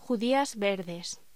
Locución: Judías verdes
voz